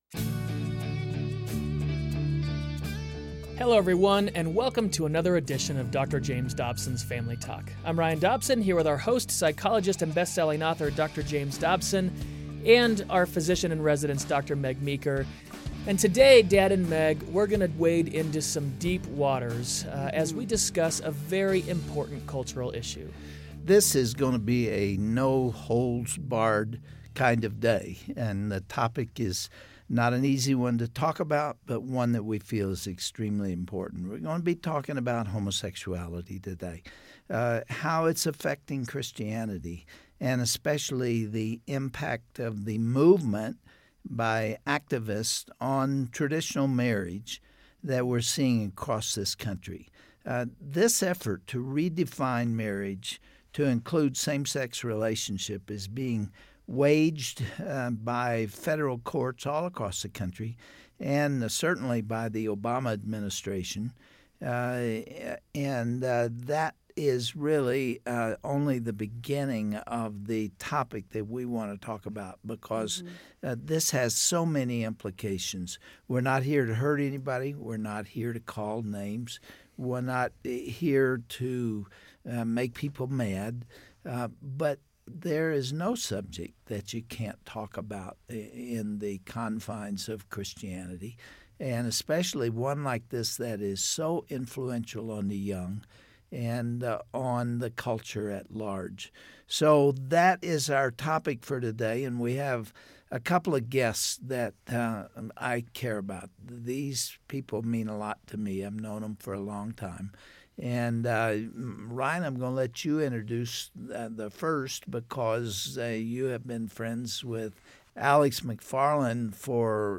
Homosexuality may be the most significant issue Christians face in our modern culture. On the next edition of Family Talk, Dr. James Dobson interviews a panel of guests, on how to approach the issue of homosexuality